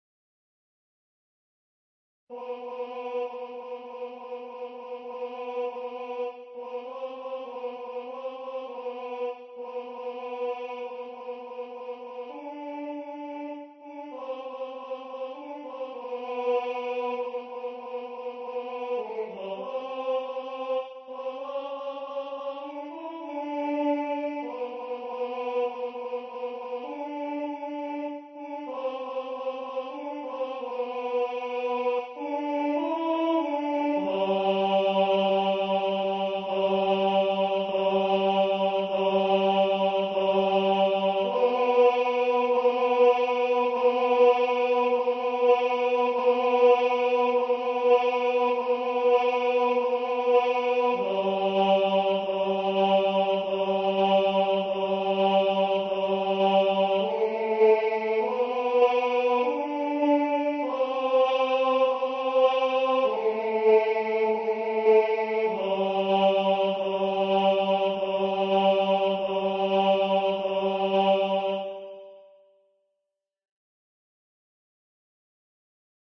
lestroiscloches-tenor.mp3